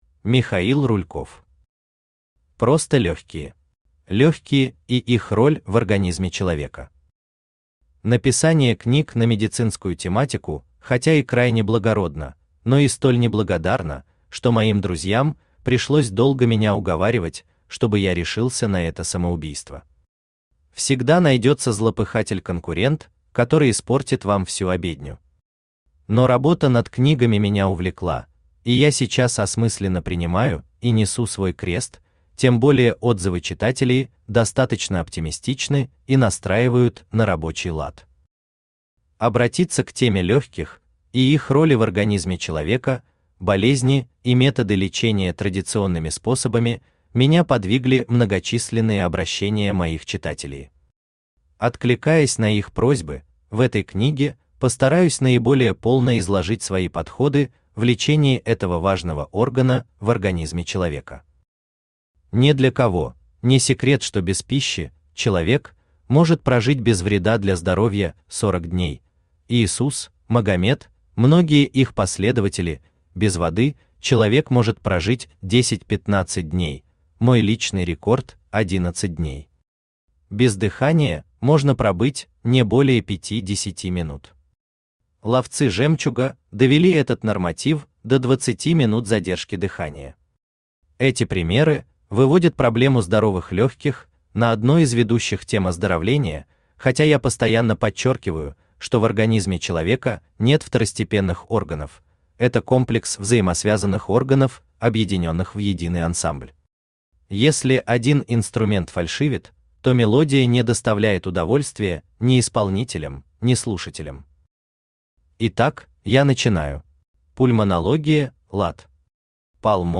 Аудиокнига Просто легкие | Библиотека аудиокниг
Aудиокнига Просто легкие Автор Михаил Михайлович Рульков Читает аудиокнигу Авточтец ЛитРес.